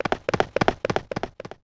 The Sounds of Horse Hooves
Horsesrestoredown.wav